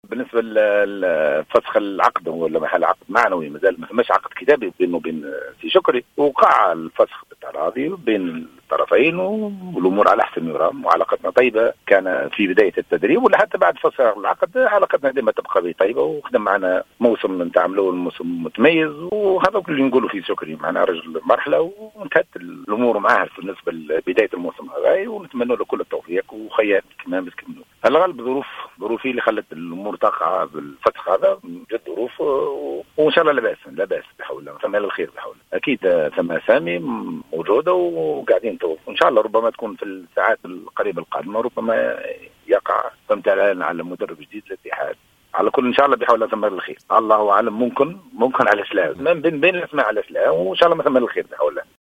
في تصريح لإذاعة الجوهرة أف أم